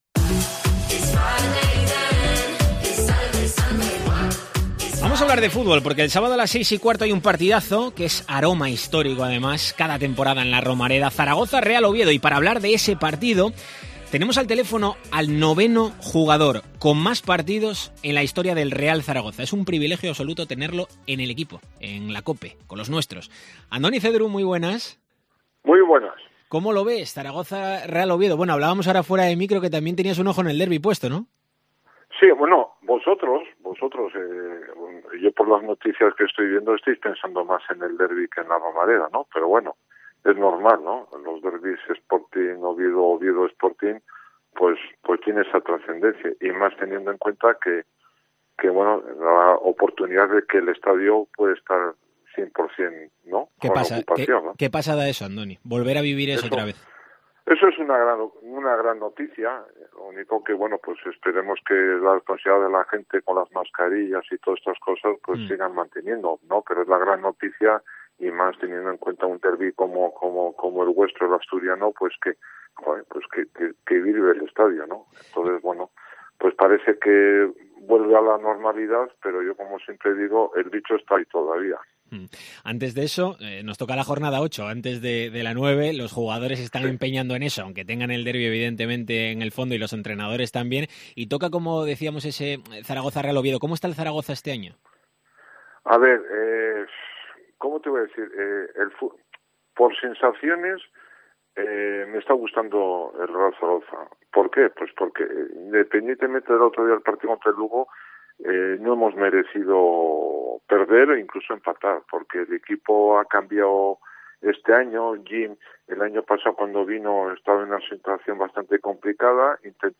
AUDIO: Entrevista con el histórico guardameta que vistió en 361 ocasiones la elástica del Real Zaragoza